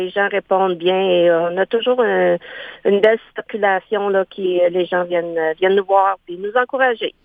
Marie-Anne Poulin, conseillère municipale, indique que le Rendez-vous du Printemps à Messines est un événement annuel très attendu :